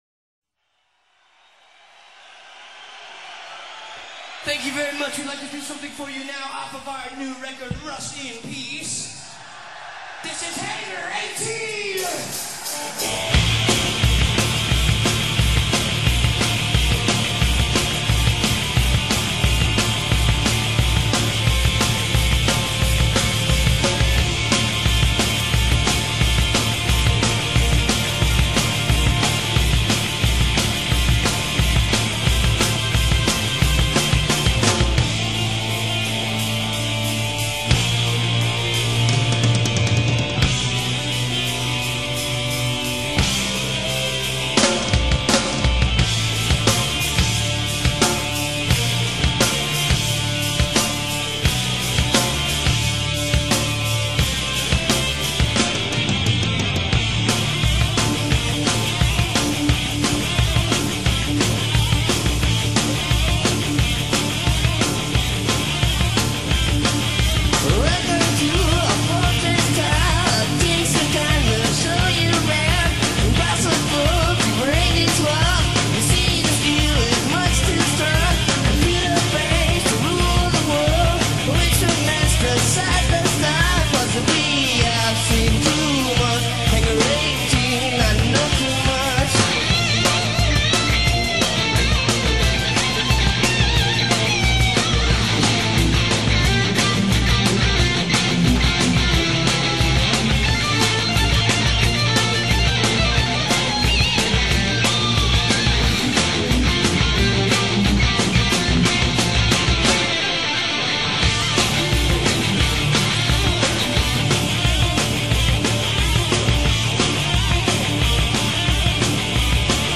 Live 90